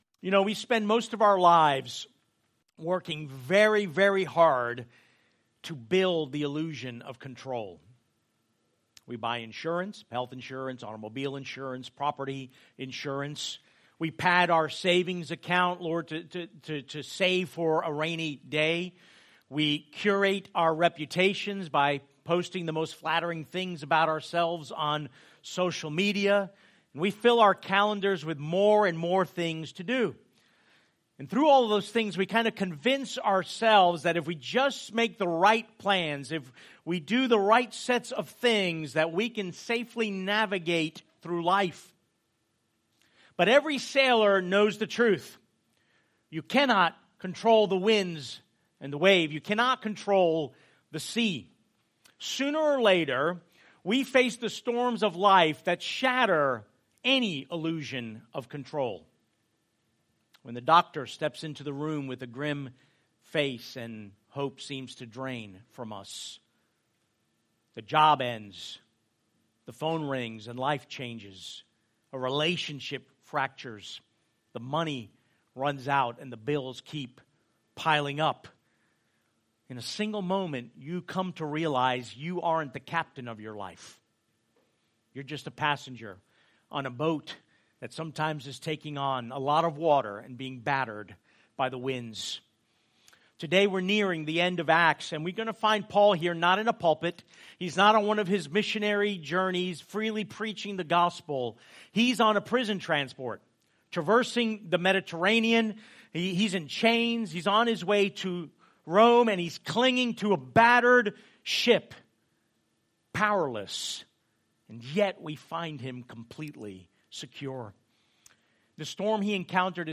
Sent Church Lake Mary, Longwood, Sanford FL | Sermons